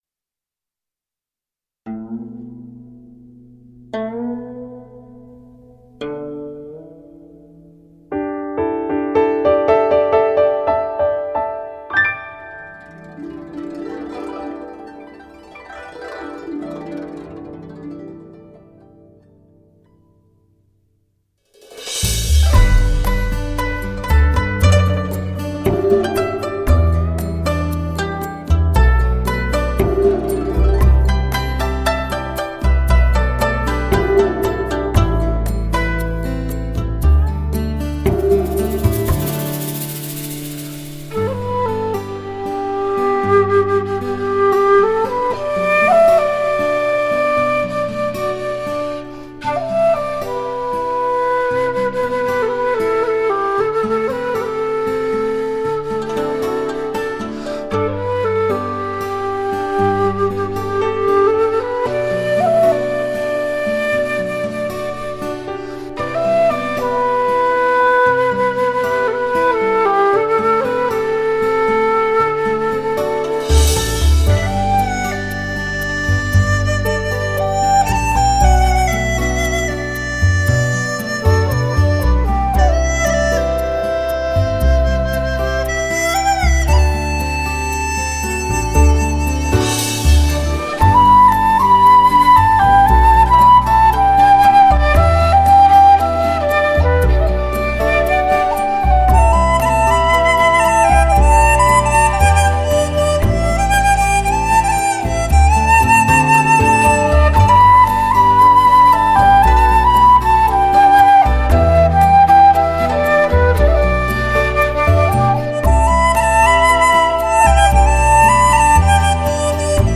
极致的发烧录音，HIFI靓声，清纯无比
传扬中国文化格调唱腔婉转流畅细腻动人，极致的发烧录音，充满诱人的成熟韵味！
箫与竹笛对话